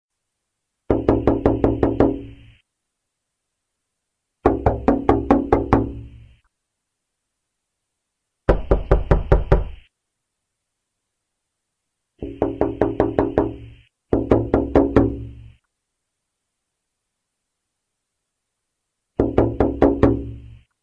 window-knock_25315.mp3